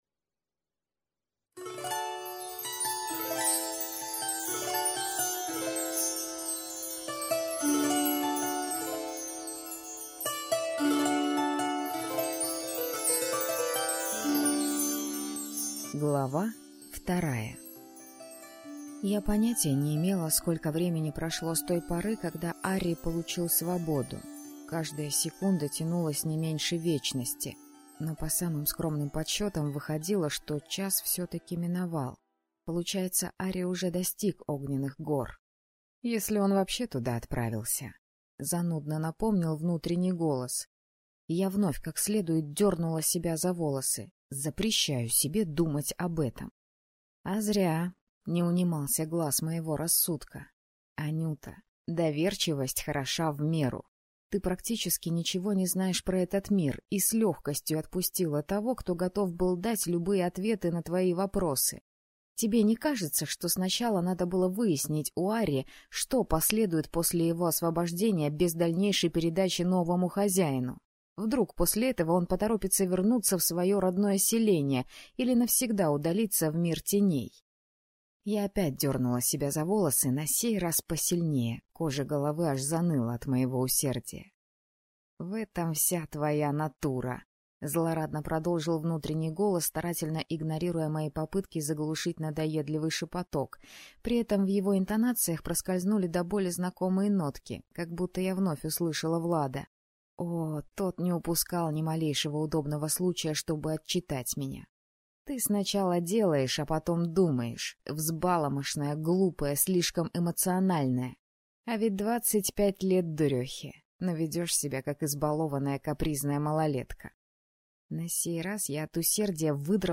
Аудиокнига Прыжок под венец - купить, скачать и слушать онлайн | КнигоПоиск